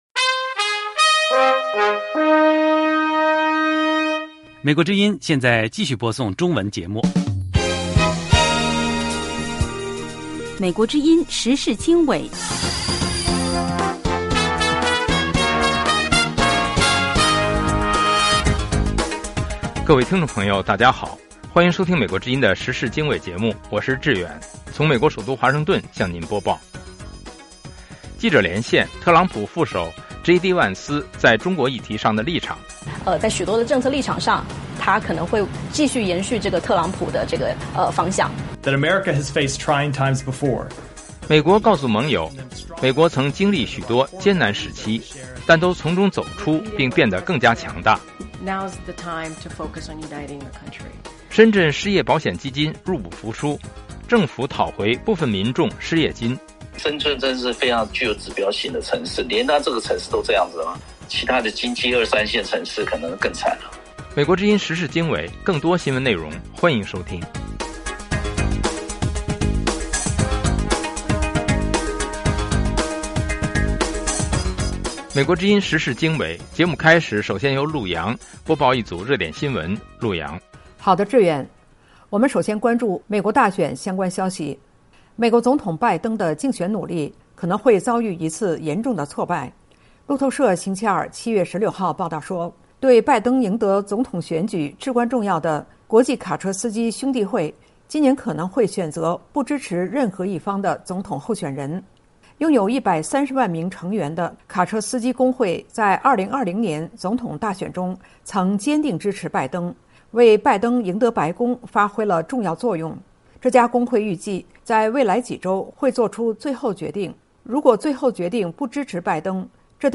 时事经纬(2024年7月17日) 记者连线：特朗普副手J.D.万斯在中国议题上的立场;美国告诉盟友：美国曾经历许多艰难时期,但都从中走出并变得更加强大;深圳失业保险基金入不敷出 政府讨回部分民众失业金